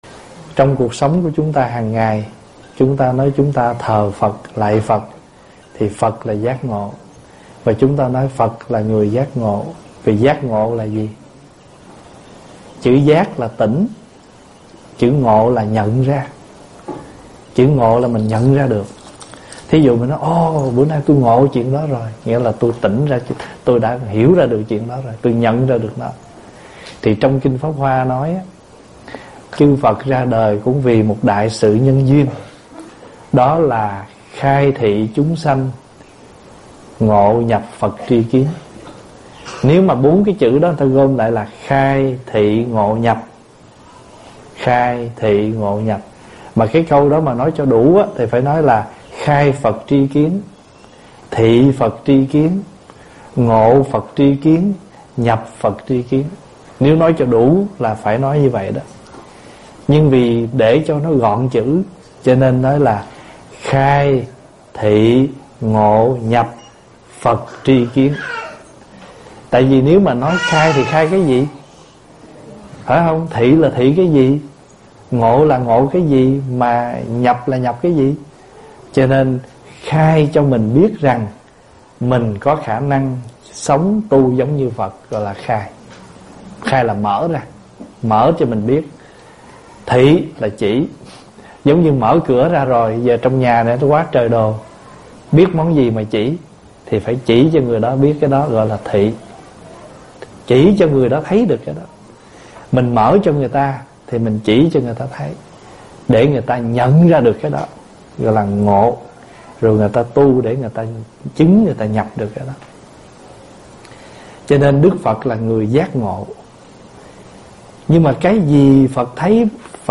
035 Khổ Đau là nhân duyên GIÁC NGỘ - Vấn Đáp.mp3